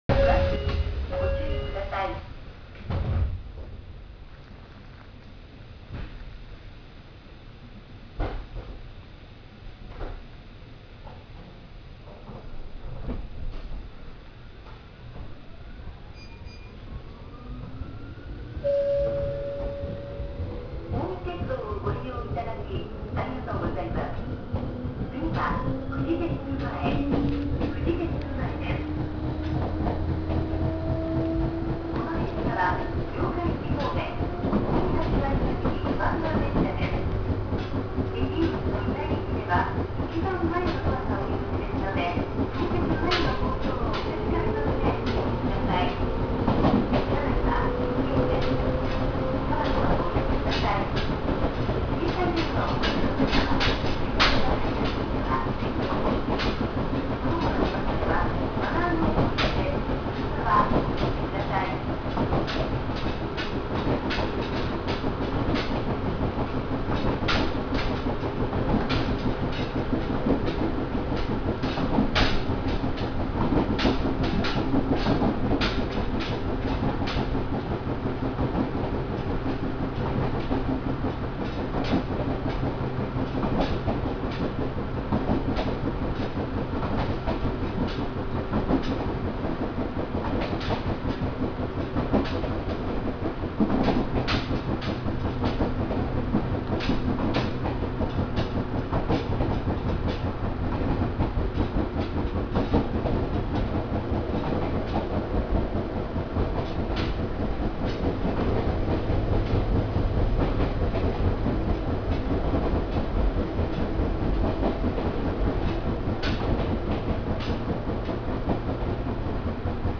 ・100形走行音
【本線】米原〜フジテック前（3分47秒：1.2MB）
当然と言えば当然なのですが、西武101系そのままの走行音です。ドアチャイムは新設された物ではありますが、その音も西武の物とほぼ同じなので新鮮味はないかも…。